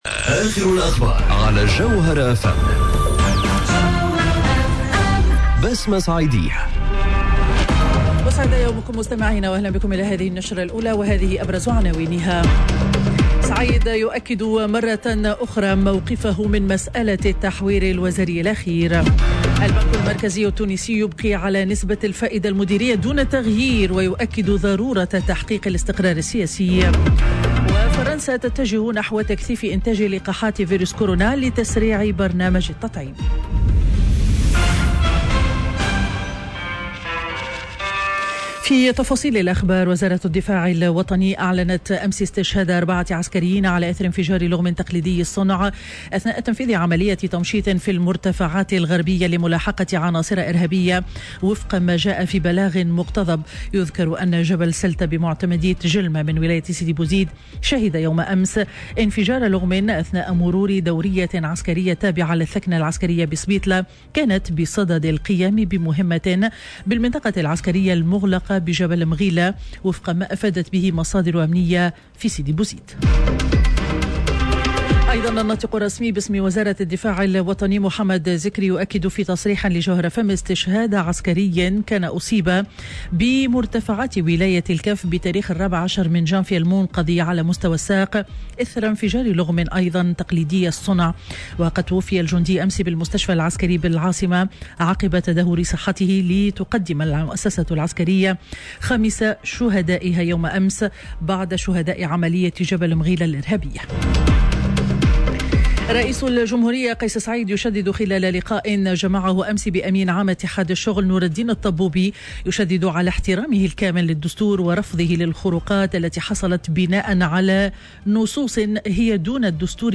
نشرة أخبار السابعة صباحا ليوم الخميس 04 فيفري 2021